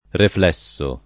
reflesso [ refl $SS o ]